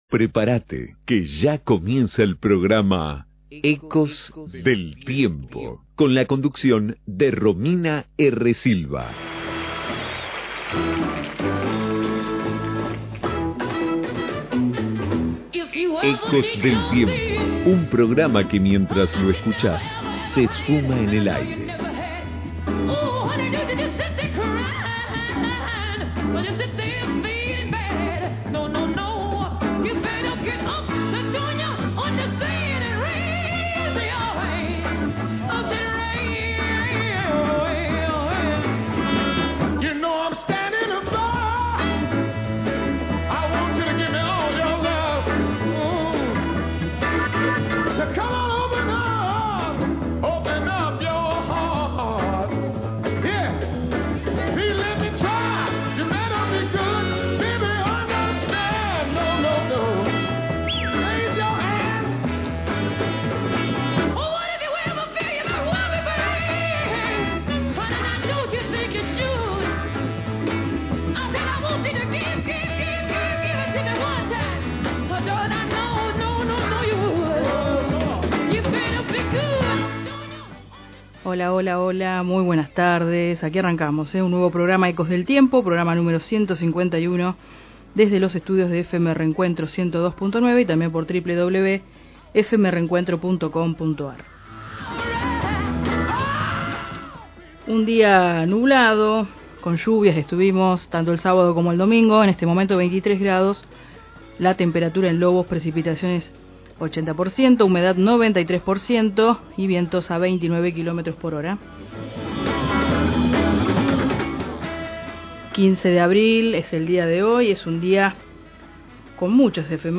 🎙🙂 Entrevista